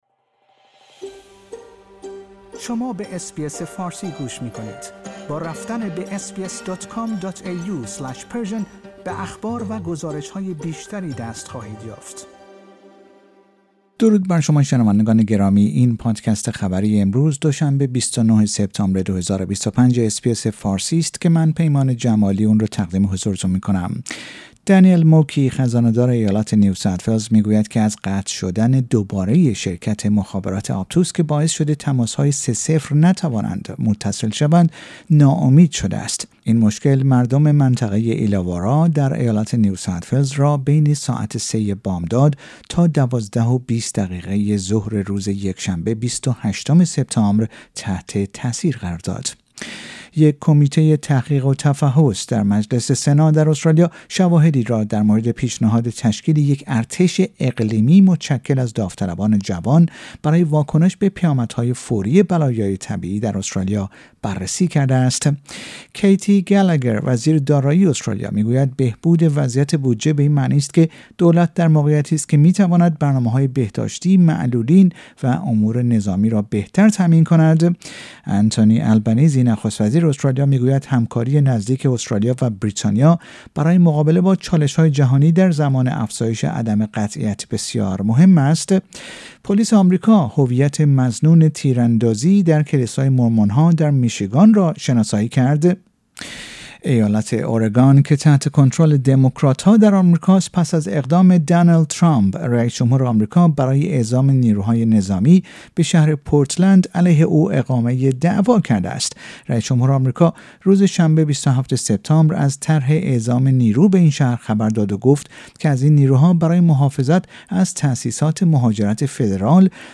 در این پادکست خبری مهمترین اخبار روز دوشنبه ۲۹ سپتامبر ارائه شده است.